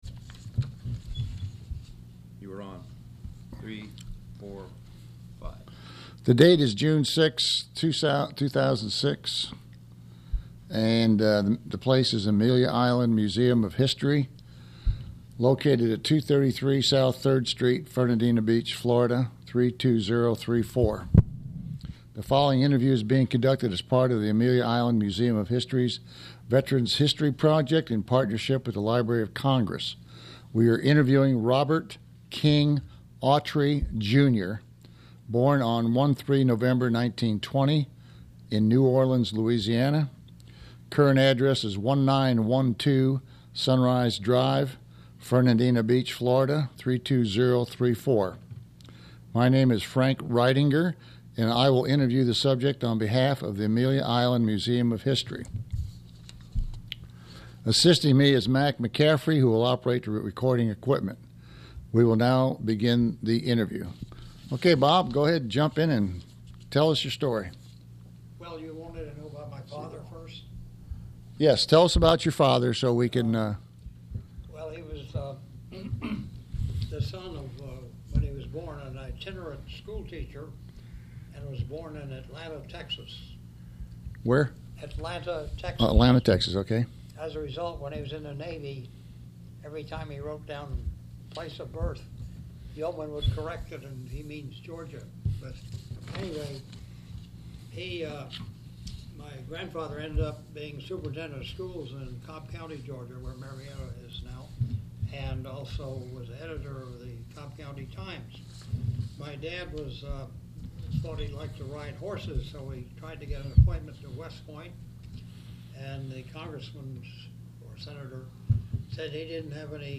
Click Here to play the Oral History Recording.